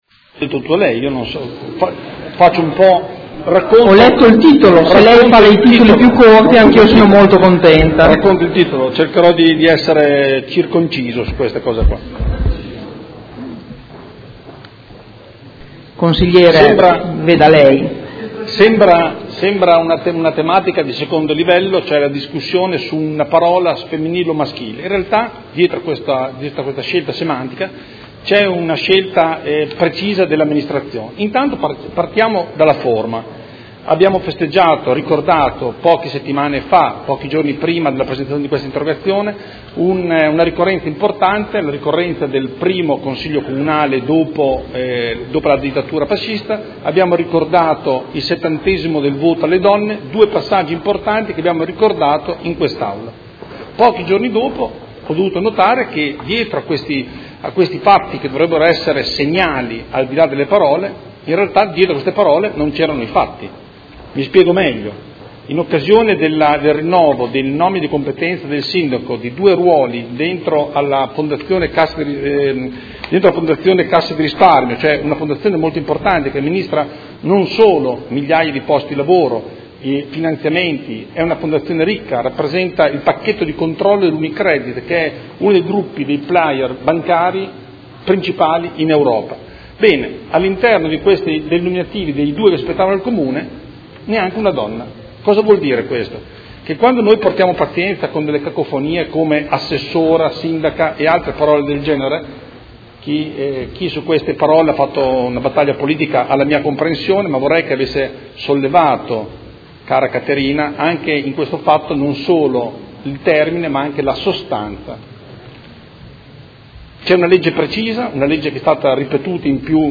Andrea Galli — Sito Audio Consiglio Comunale
Seduta del 7 luglio.